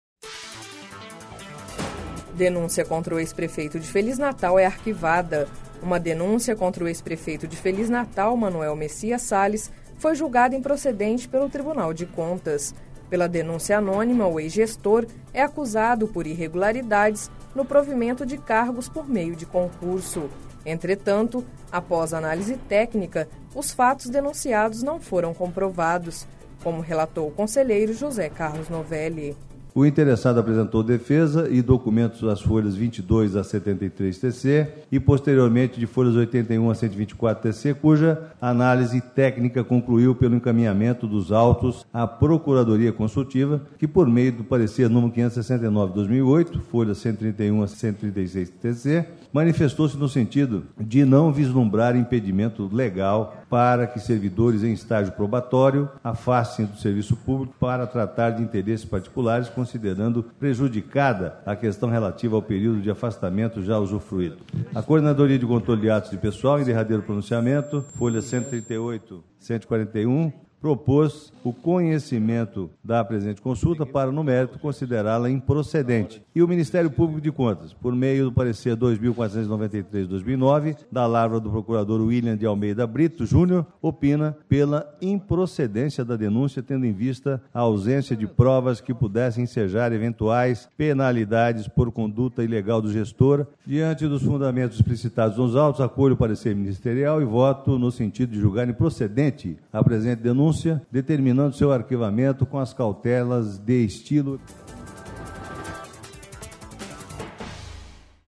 Sonora: José Carlos Novelli – conselheiro TCE-MT